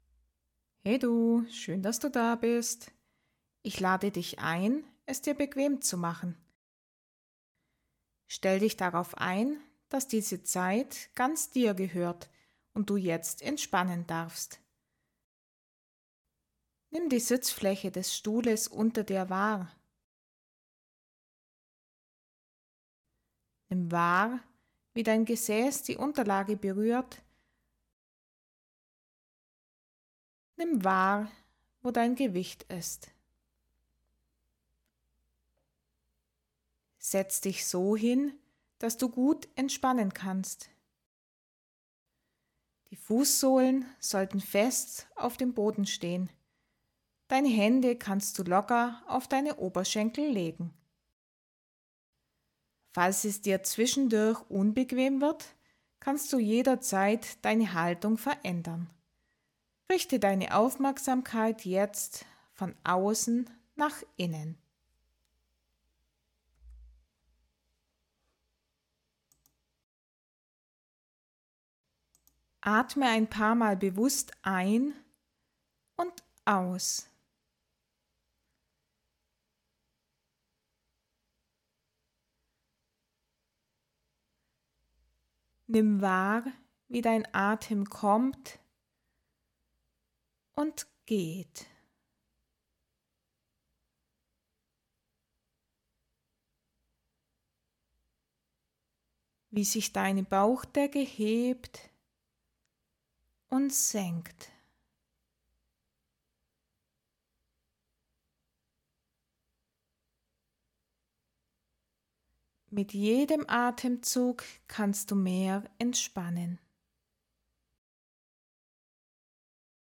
Autogenes Training: Eine geführte Übung für Entspannung und Stressabbau | Hypnose & Meditation zur Tiefenentspannung (S4) ~ Entspannung fürs Büro Podcast